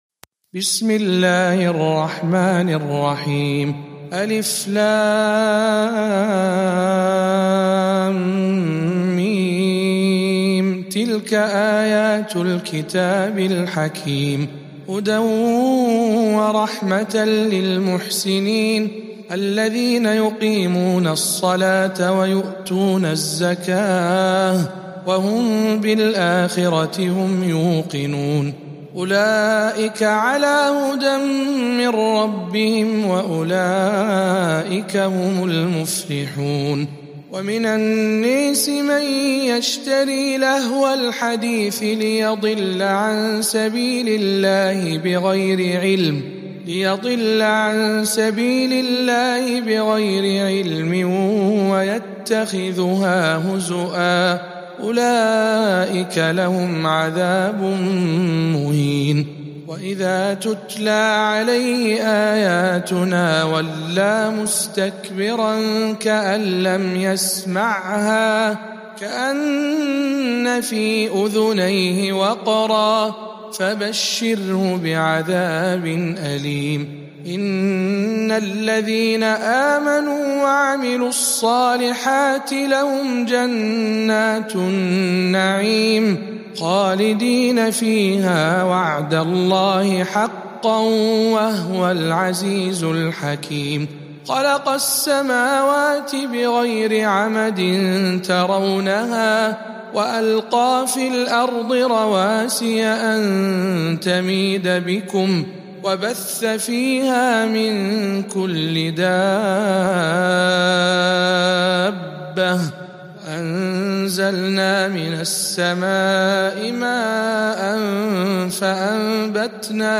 سورة لقمان برواية الدوري عن أبي عمرو